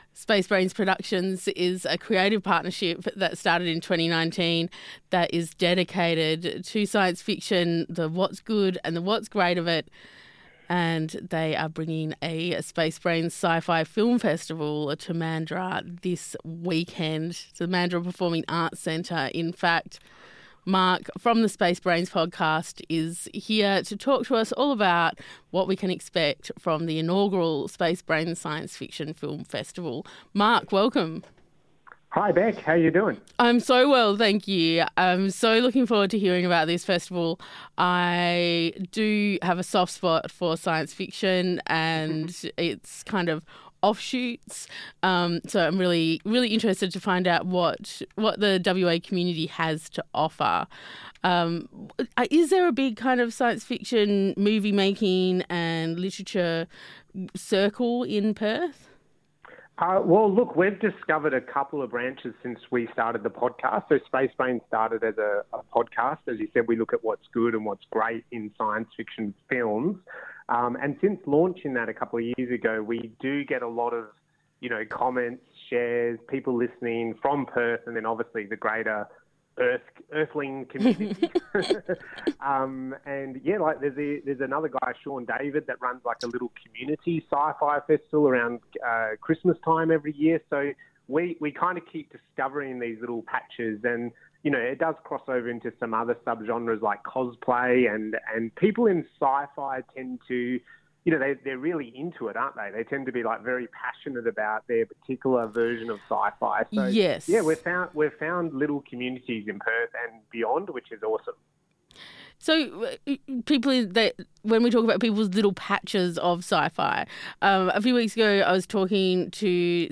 space-brains-productions-interview.mp3